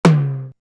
LM-2_TOM_1_TL.wav